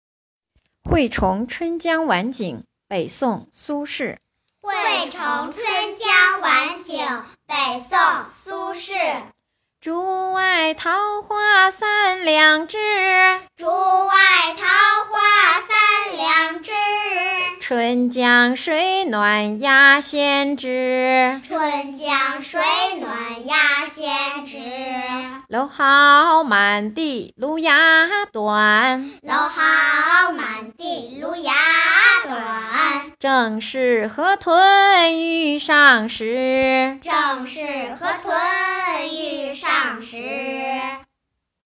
《惠崇》学吟诵